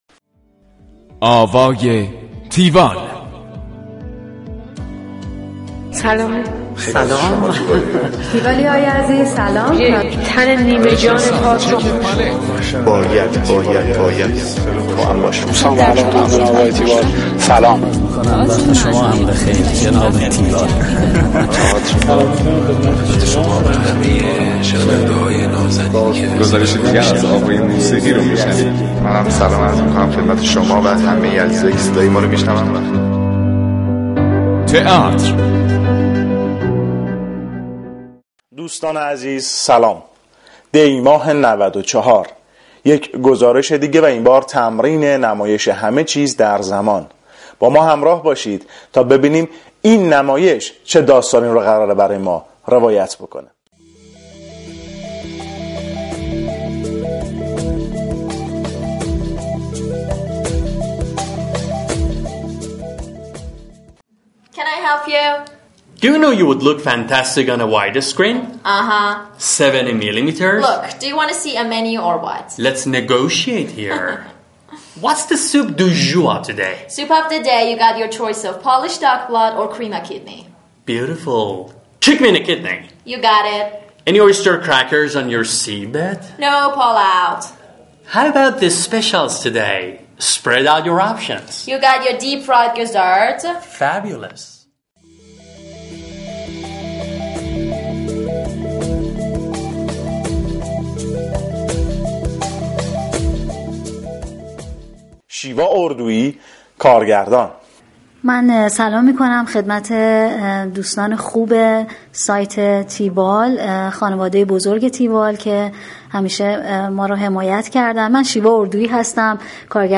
گزارش آوای تیوال از نمایش همه چیز در زمان